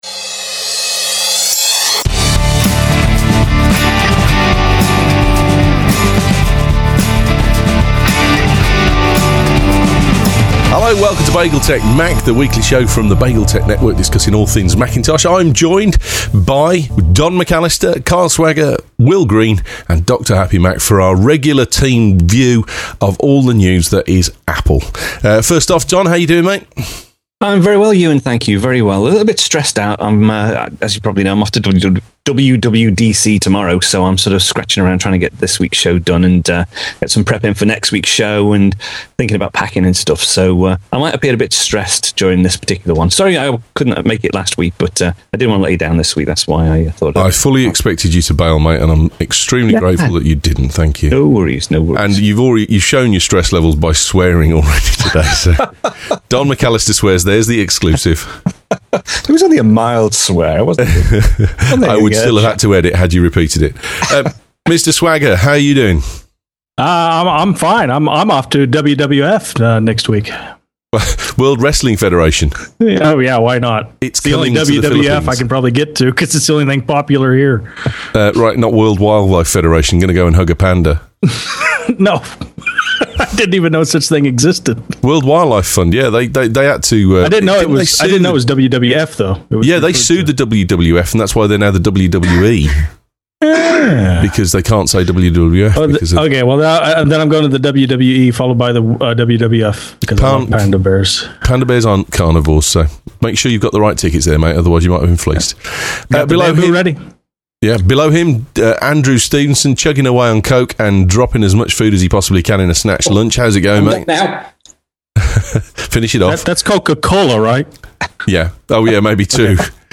So the Mac Crew sit and debate whats happening at WWDC in the coming week.